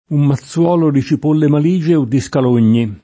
um maZZU0lo di ©ip1lle mal&Je o ddi Skal1n’n’i] (Boccaccio)